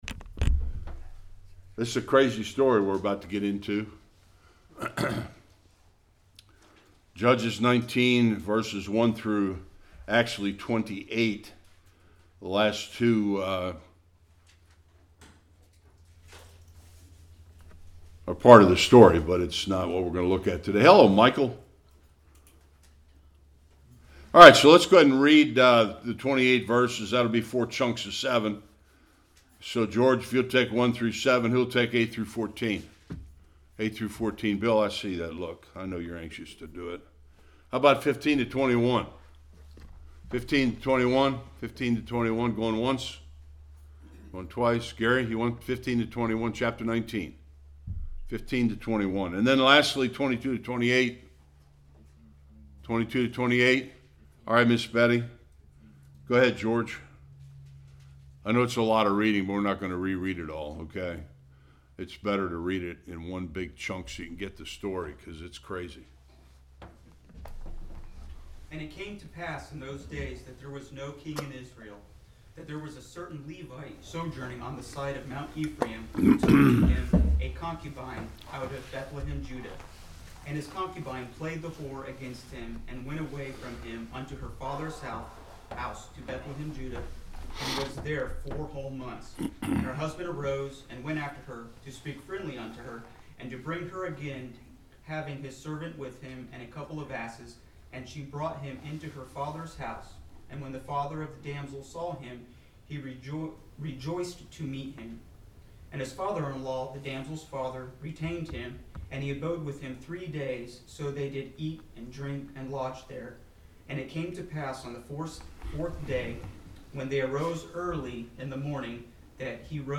1-30 Service Type: Sunday School A tragic story of a Levite who was a horrible man.